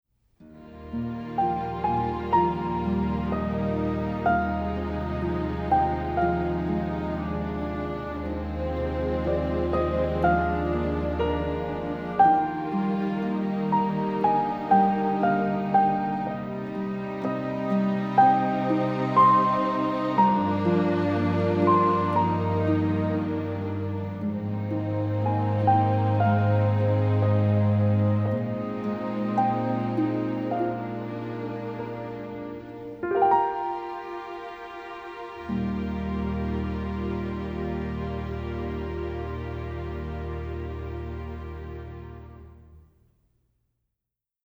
Sound clips from the film score